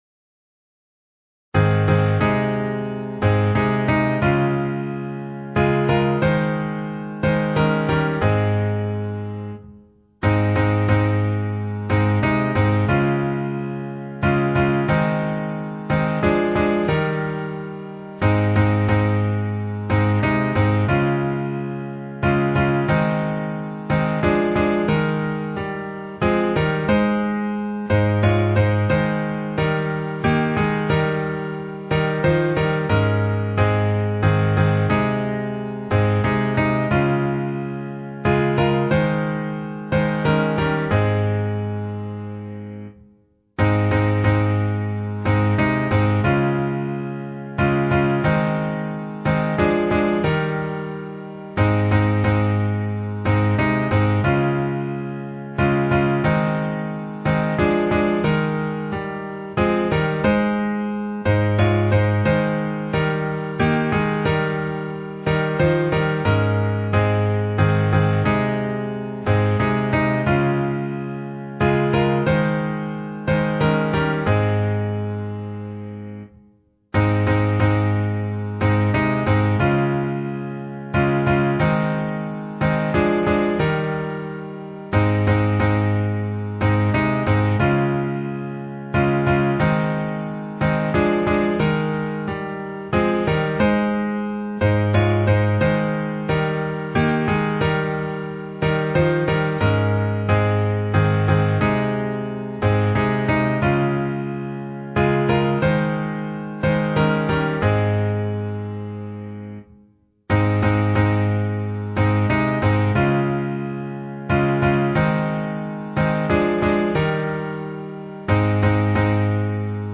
A collection of over a thousand old country hymns, enjoyed for generations. audio, video and downloadable PDFs
Key: A♭